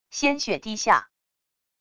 鲜血滴下wav音频